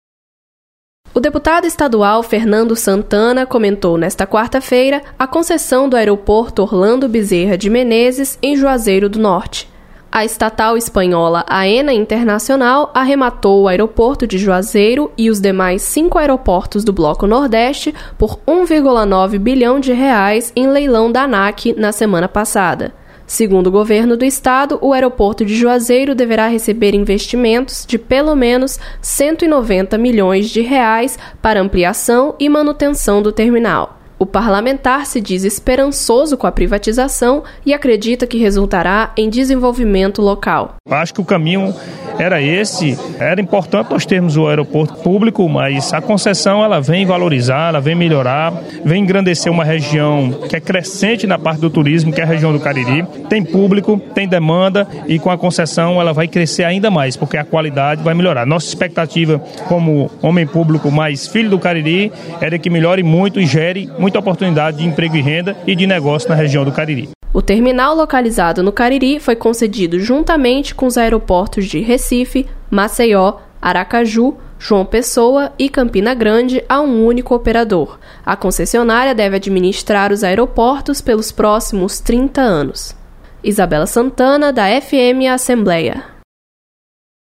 Deputado comenta concessão do Aeroporto de Juazeiro do Norte.